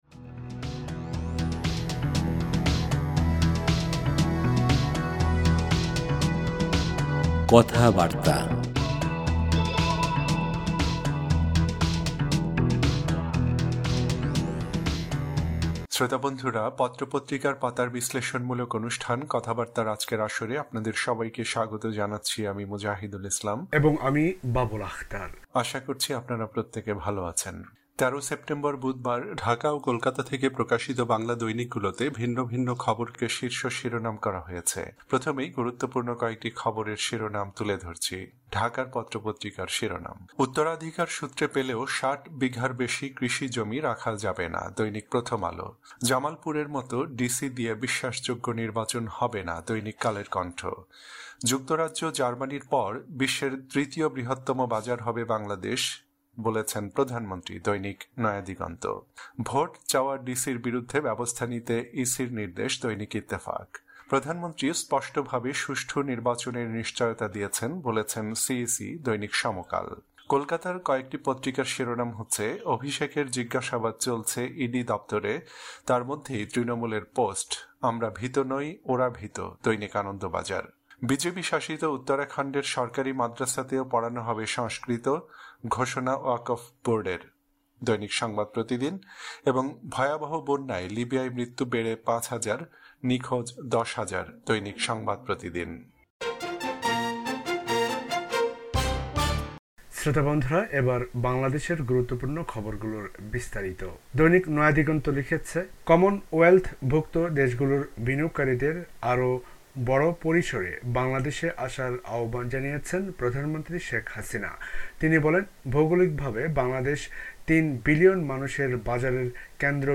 পত্রপত্রিকার পাতার বিশ্লেষণমূলক অনুষ্ঠান